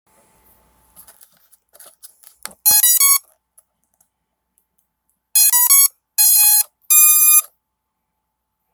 HS550 DRONE'S POWER UP SOUND